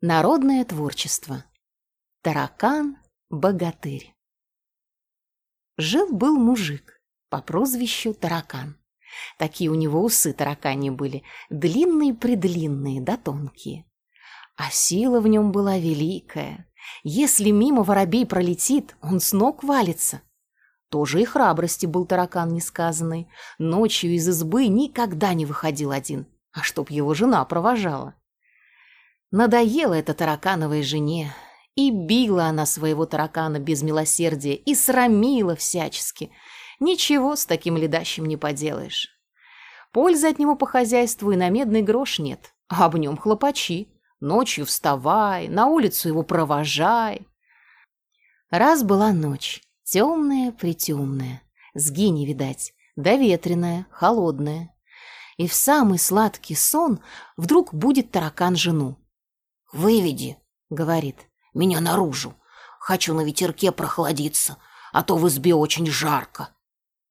Аудиокнига Таракан-богатырь | Библиотека аудиокниг